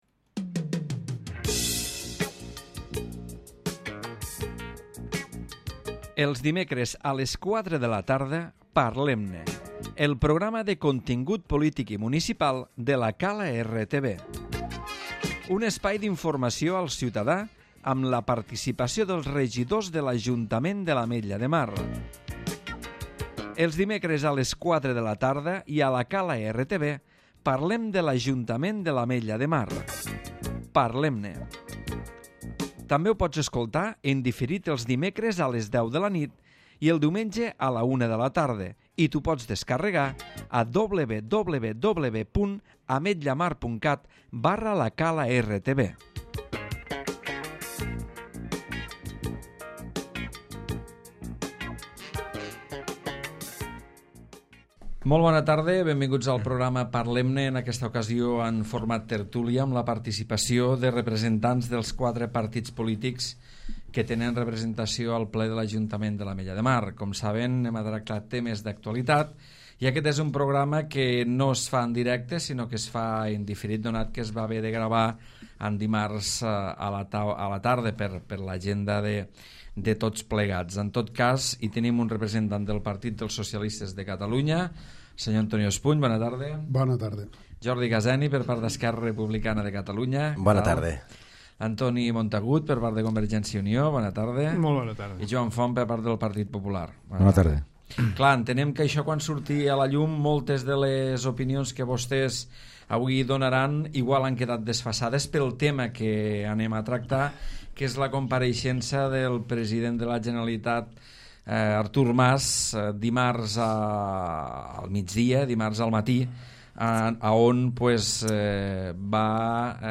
El pla alternatiu d'Artur Mas pel 9-N es debatut pels representants dels partits polítics amb representació al ple municipal.